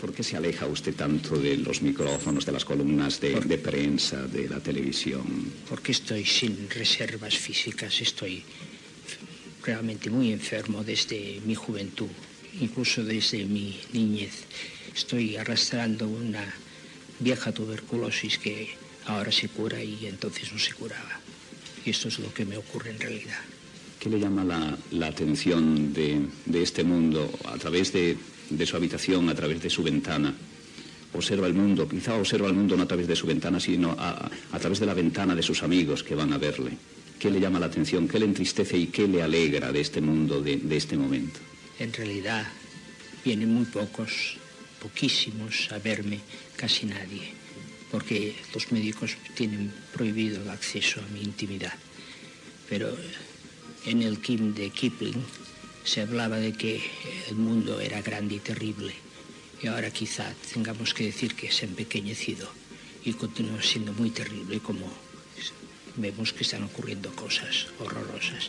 Fragment d'una entrevista al poeta Salvador Espriu
Info-entreteniment